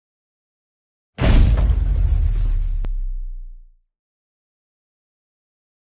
Tiếng Điện Nổ (âm thanh thực)
Thể loại: Đánh nhau, vũ khí
Description: Âm thanh mô phỏng tiếng điện giật, tia lửa tóe và nổ điện mạnh mẽ, rất phù hợp để chèn vào video kỹ xảo, dựng clip hành động, hoặc làm âm thanh phụ cho các đoạn lồng tiếng mang tính bất ngờ, kịch tính. Với chất lượng rõ nét, đây là sound effect, audio hiệu ứng không thể thiếu trong kho âm thanh edit video chuyên nghiệp.
tieng-dien-no-am-thanh-thuc-www_tiengdong_com.mp3